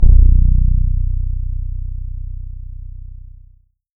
SYN FRETLE-R.wav